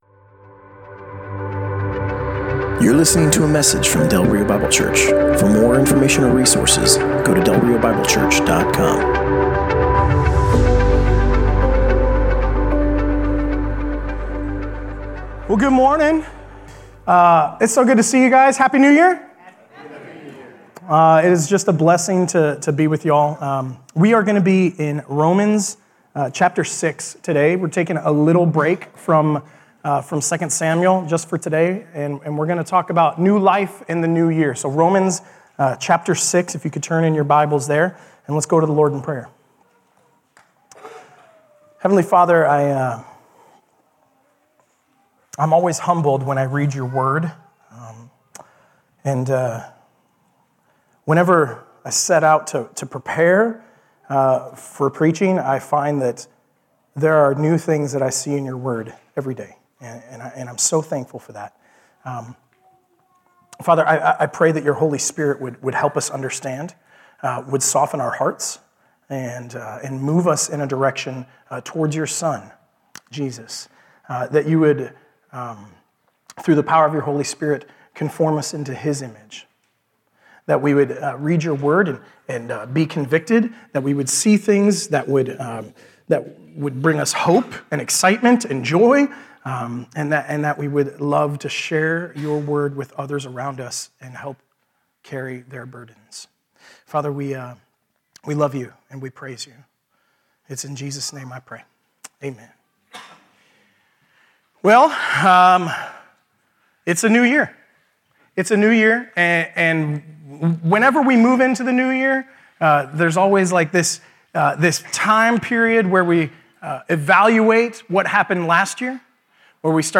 Passage: Romans 6: 1-14 Service Type: Sunday Morning